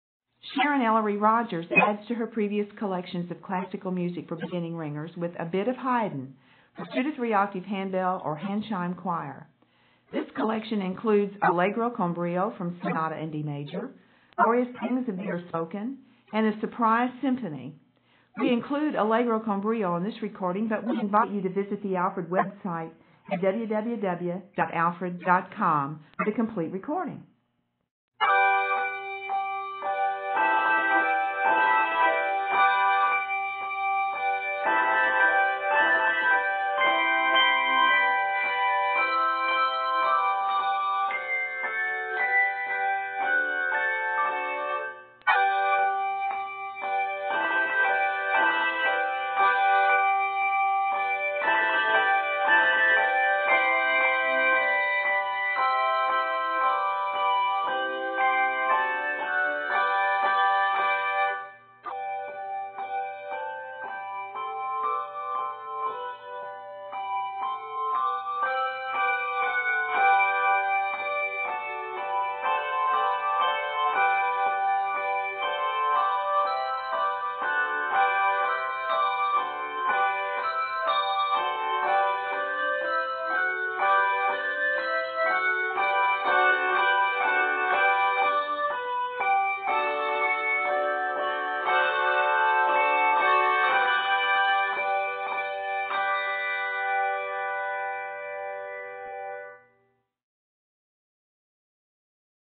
2-3 octave handbell or handchime choir